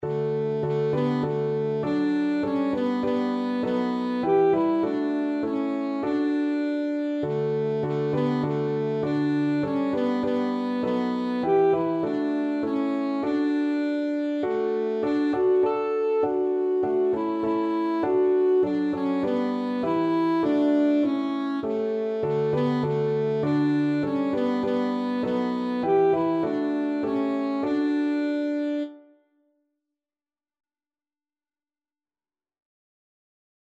Christian
Alto Saxophone
3/4 (View more 3/4 Music)
Classical (View more Classical Saxophone Music)